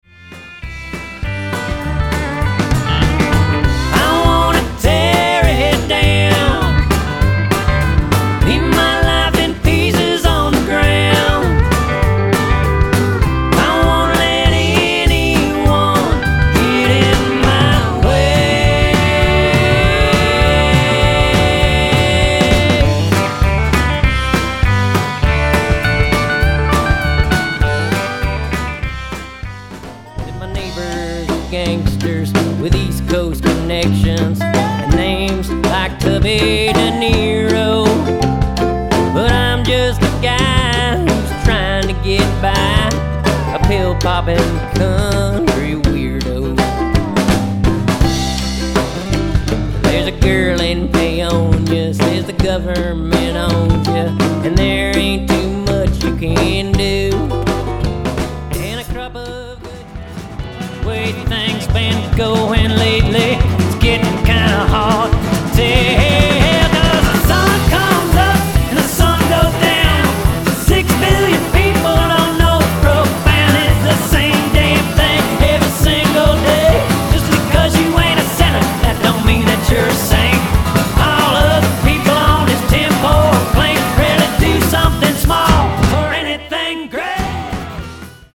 Genre/Style: Country, alternative country, retro